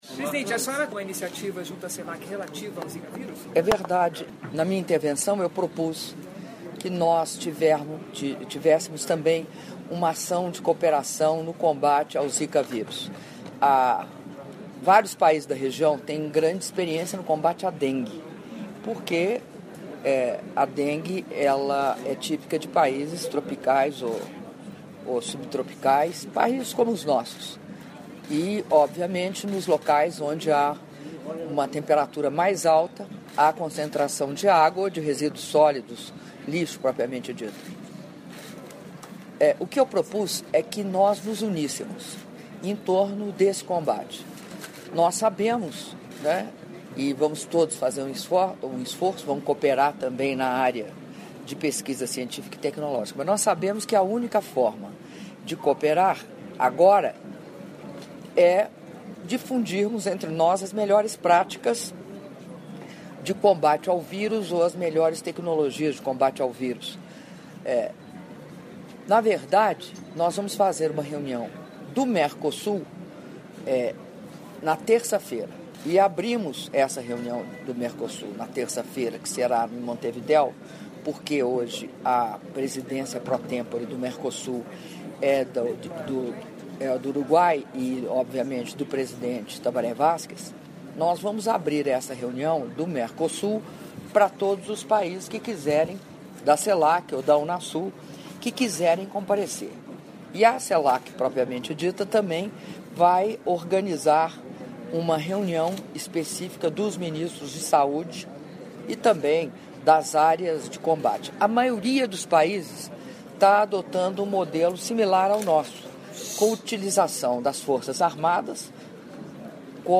Áudio da entrevista coletiva concedida pela Presidenta da República, Dilma Rousseff, após sessão de abertura da IV Cúpula de Chefes de Estado e de Governo da Comunidade de Estados Latino-Americanos e Caribenhos - CELAC - Quito/Equador (07min38s)